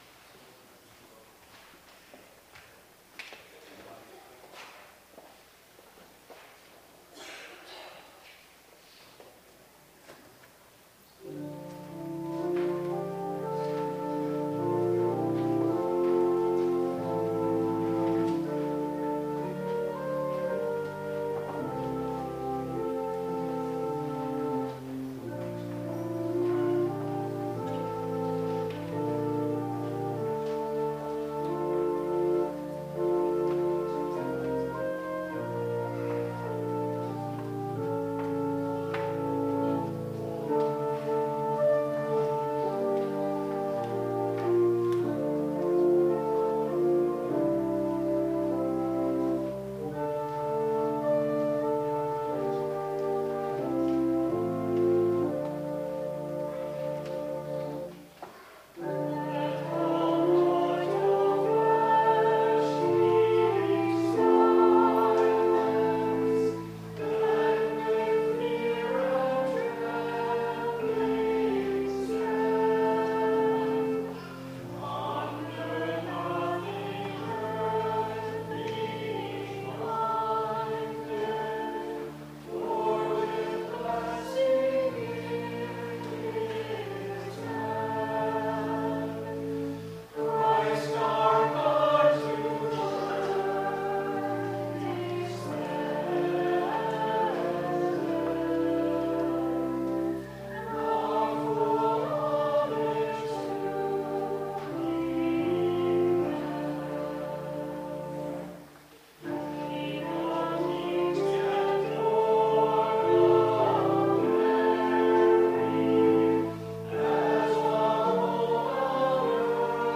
Hymn 324 and 810 sung by St Peter’s Choir